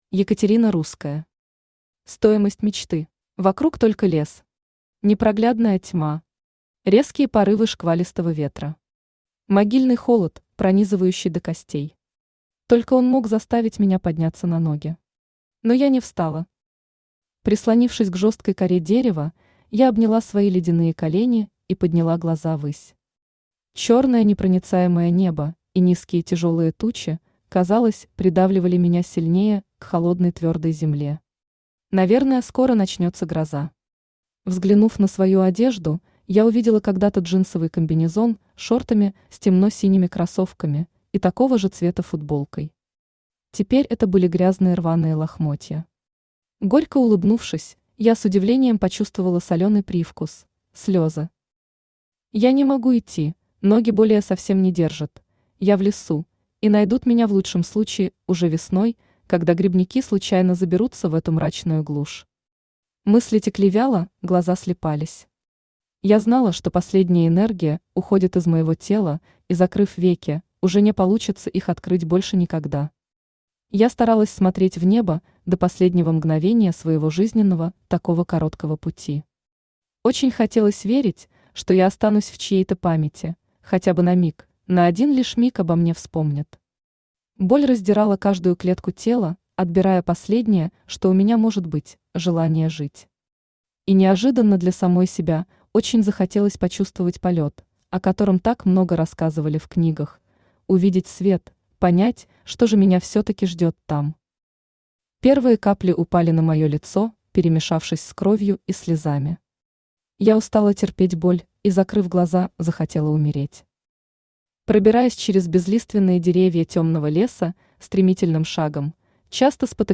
Аудиокнига Стоимость мечты | Библиотека аудиокниг
Aудиокнига Стоимость мечты Автор Екатерина Русская Читает аудиокнигу Авточтец ЛитРес.